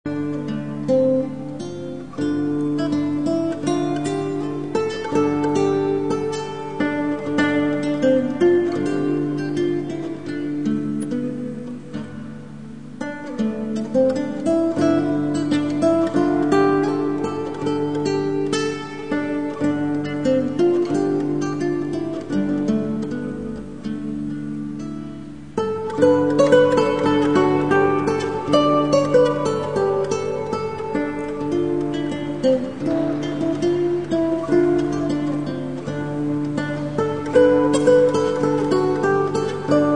Machmal spiele ich im Regensburger Schottenportal Gitarre.
Es ist ein superschöner, kubischer Vorbau vor dem Portal aus Glas. Folge..eine wunderbare Akkustik-
KlangBsp: Oswald von Wolkenstein mit meiner Klassik Gitarre.